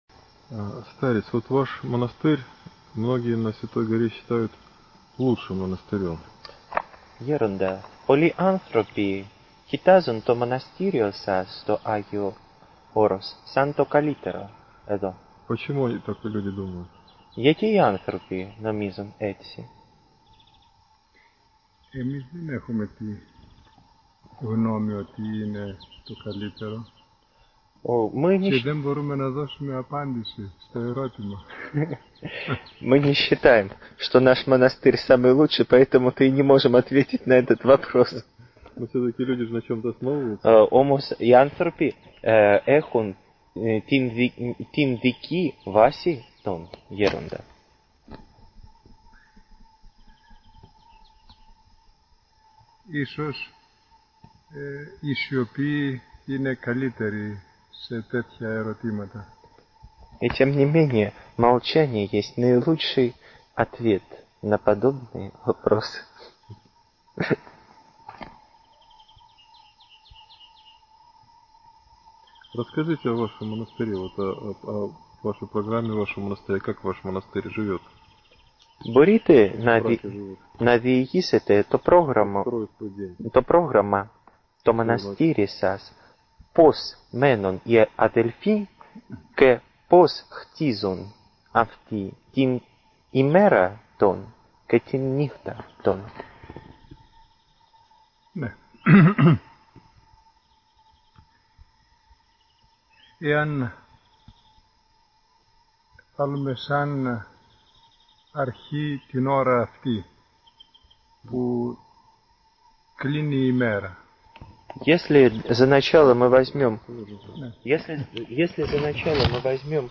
Беседа с русскими братьями. Монастырь Филофей. Св.Гора Афон (ок. 2000 г.).